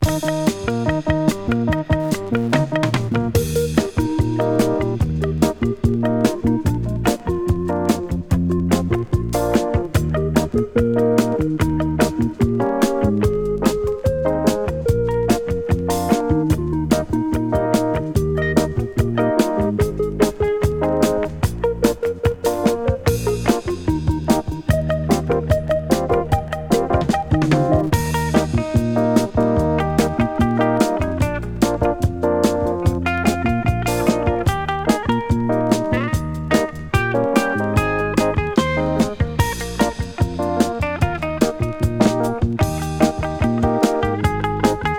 Jazz, Pop, Easy Listening　Canada　12inchレコード　33rpm　Stereo